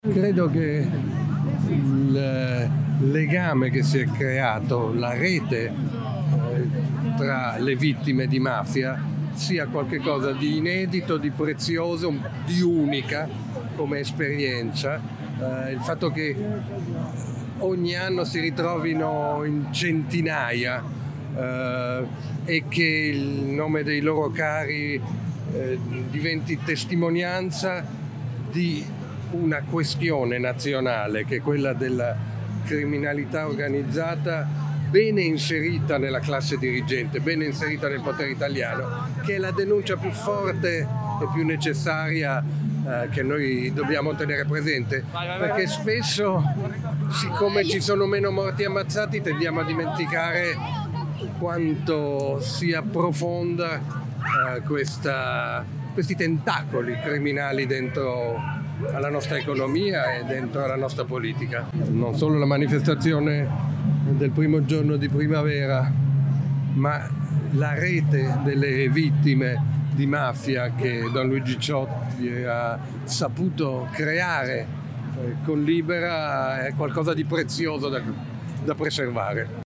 Abbiamo raccolto alcune voci e riflessioni provenienti dalla società civile, dalle associazioni, dalla politica
L’intervista a Gad Lerner, giornalista e conduttore televisivo